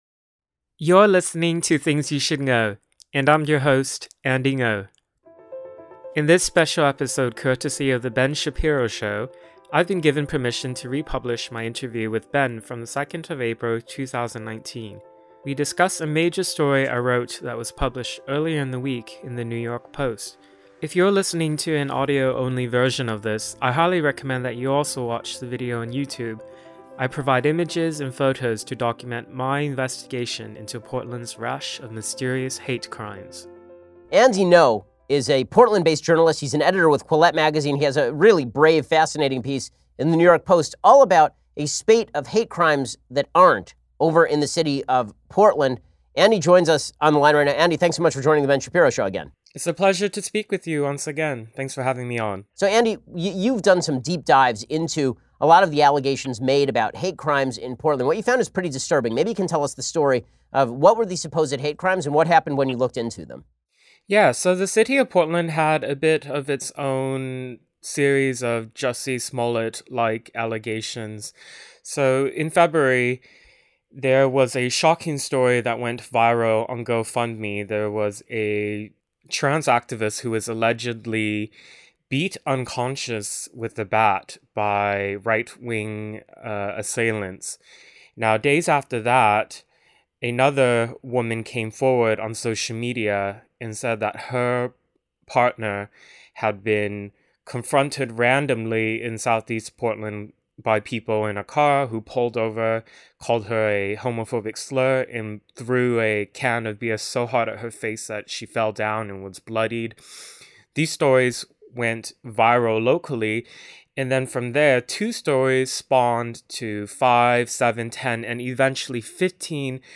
Many of you may not have heard my 2019–20 podcast episodes from "Things You Should Ngo." Here is my interview with Ben Shapiro about an antifa gay hate crime hoax ...